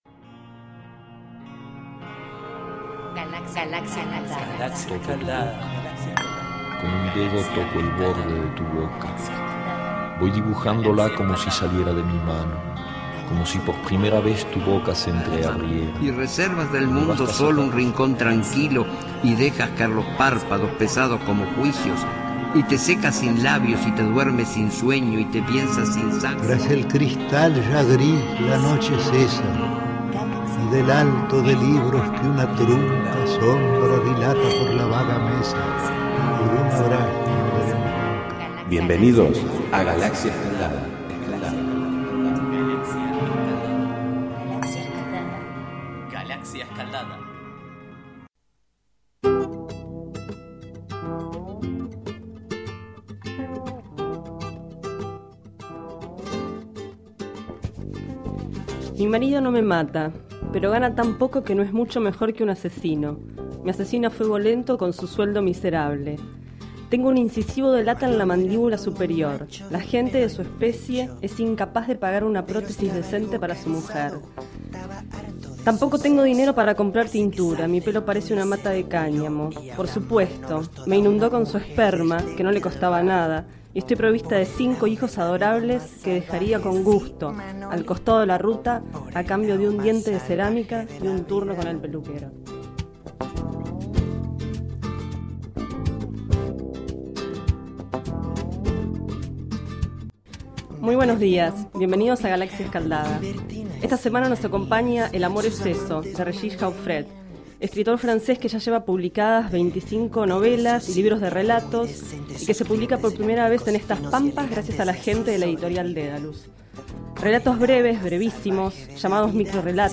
Este es el 11º micro radial, emitido en los programas Enredados, de la Red de Cultura de Boedo, y En Ayunas, el mañanero de Boedo, por FMBoedo, realizado el 19 de mayo de 2012, sobre el libro El amor es eso, de Regis Jauffret.